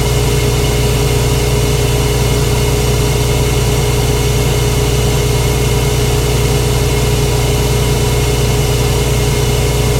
centrifugeOperate.ogg